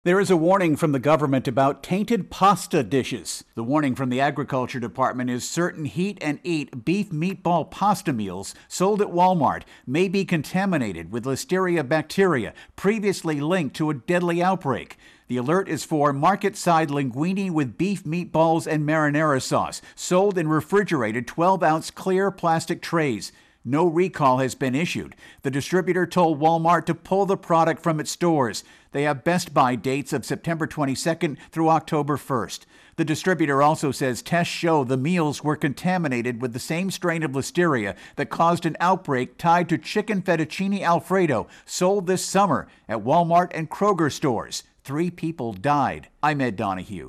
reports on a warning about meatball meals.